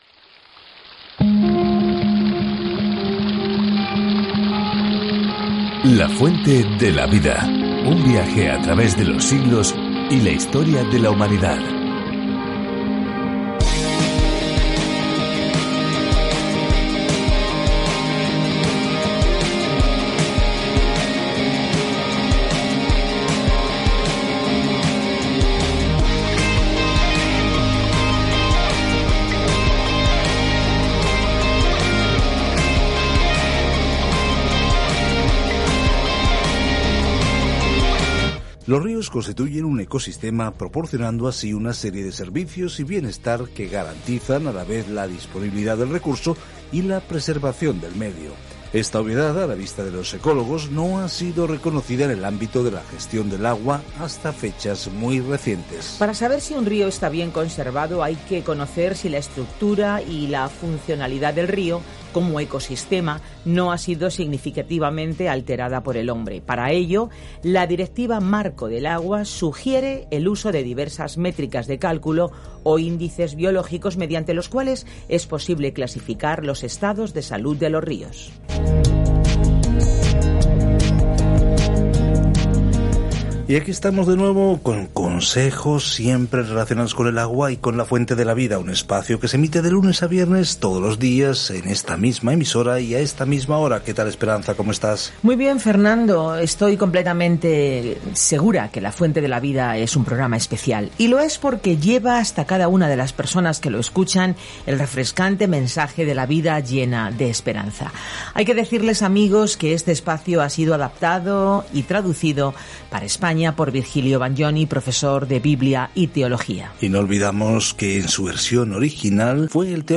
Escritura AMÓS 4:10-13 AMÓS 5:1-5 Día 8 Iniciar plan Día 10 Acerca de este Plan Amós, un predicador rural, va a la gran ciudad y condena sus conductas pecaminosas, diciendo que todos somos responsables ante Dios según la luz que Él nos ha dado. Viaja diariamente a través de Amós mientras escuchas el estudio en audio y lees versículos seleccionados de la palabra de Dios.